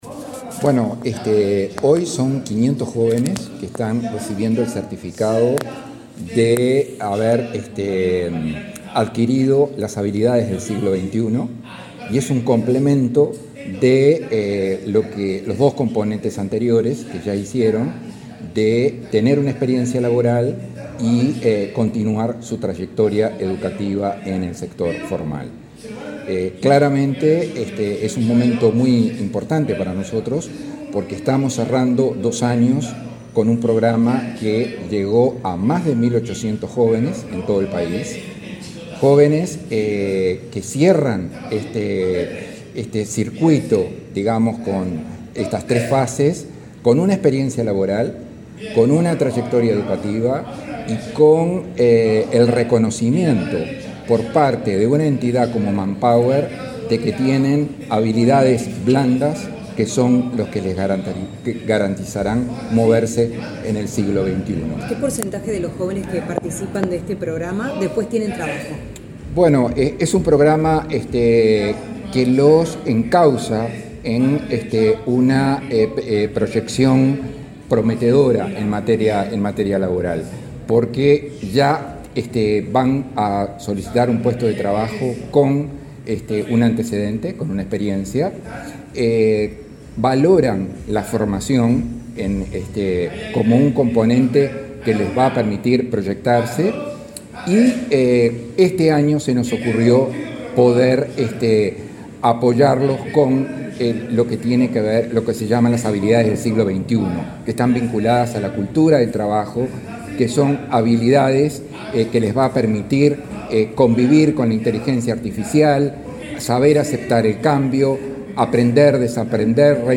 Declaraciones del director del Inefop en representación del MEC